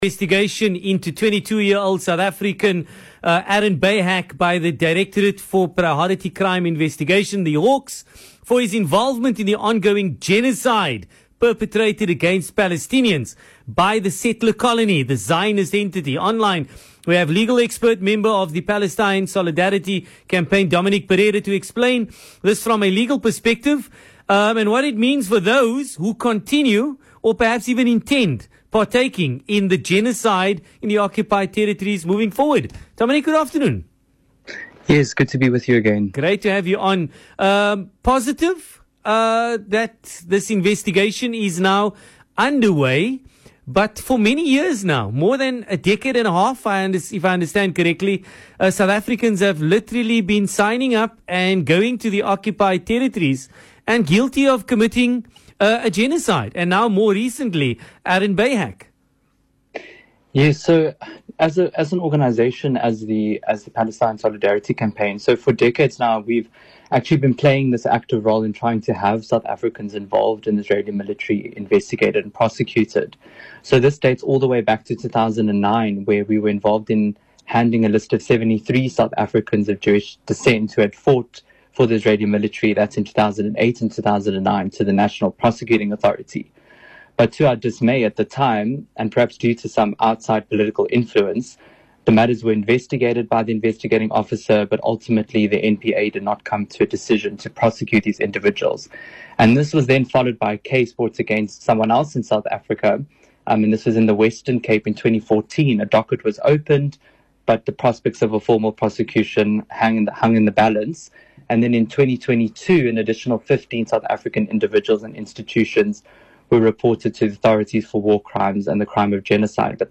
Speaking on VOC’s PM Drive on Friday, a legal expert and member of the Palestinian Solidarity Campaign